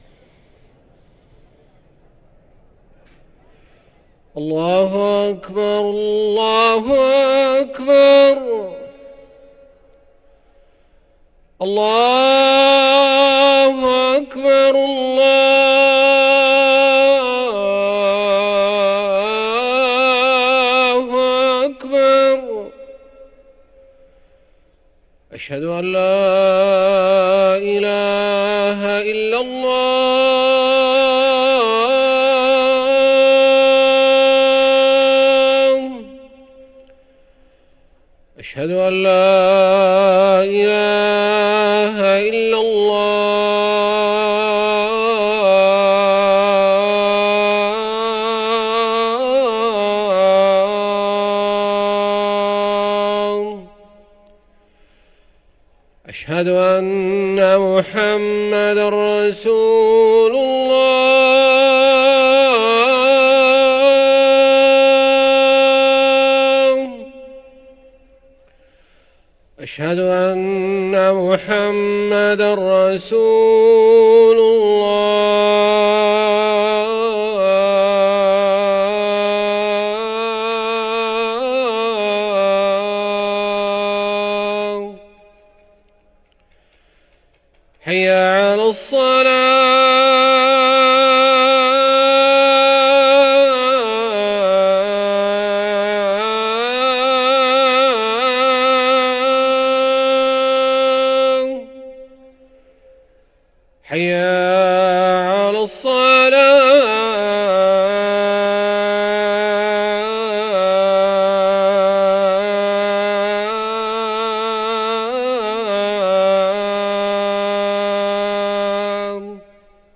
أذان الظهر
ركن الأذان